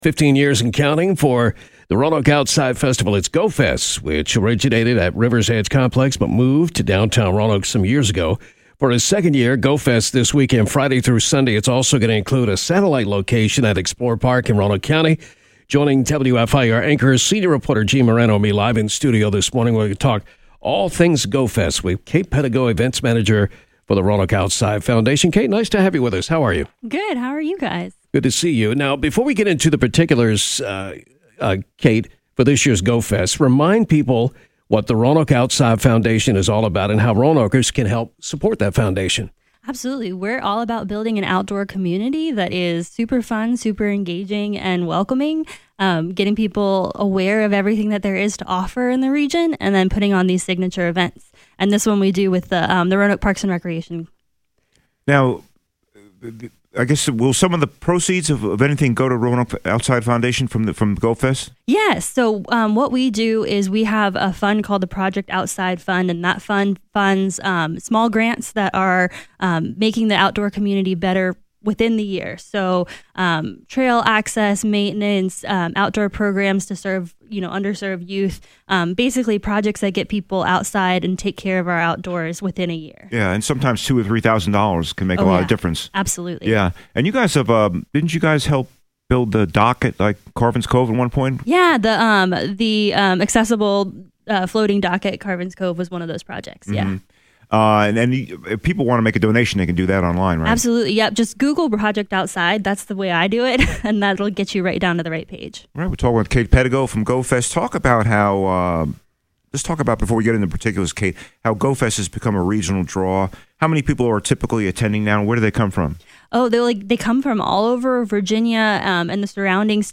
Hear the complete interview from this morning about GoFest on the link below or watch it on Facebook.